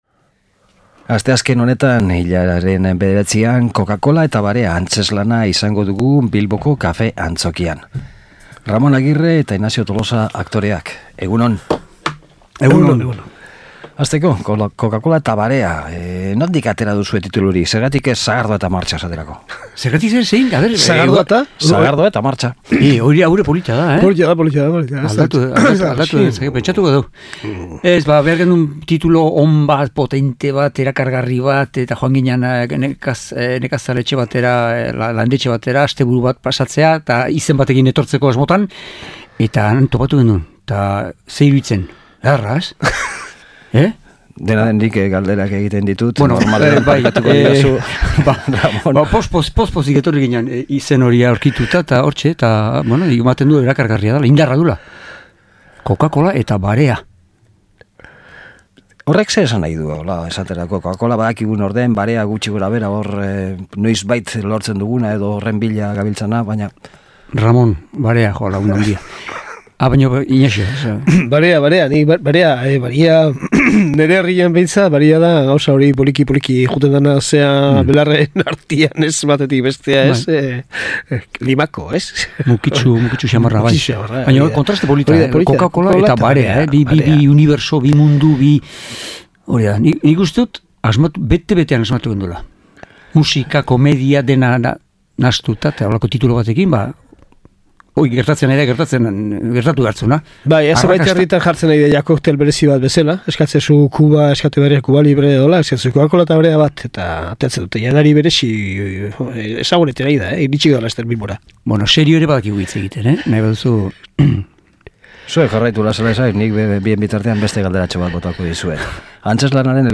Solasaldia
Aktoreek Ez Dok Hamairu, argazkia, umorea, showa, musika, historia.. hori eta gehiago kontatu digute.